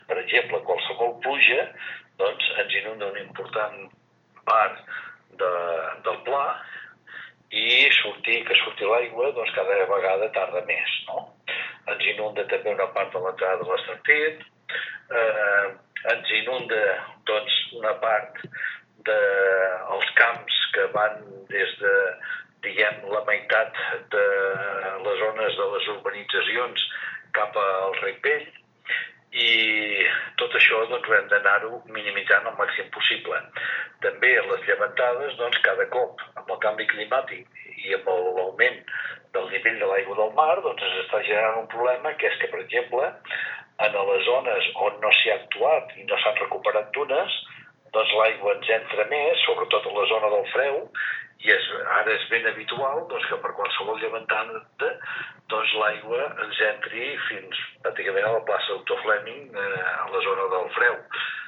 L’alcalde de Torroella de Montgrí, Jordi Colomí, explica que el canvi climàtic ha agreujat les inundacions al municipi, ara molt més intenses que fa 20 anys.